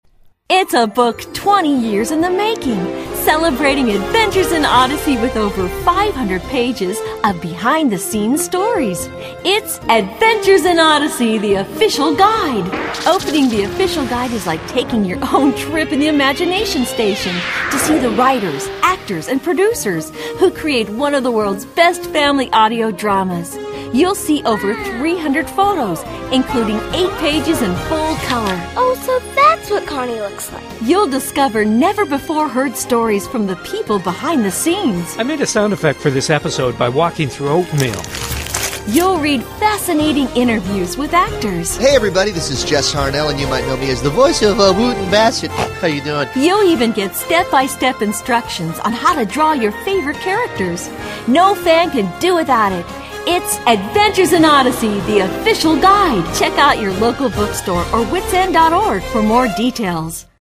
This audio promo aired to build interest in the release of "The Official Guide."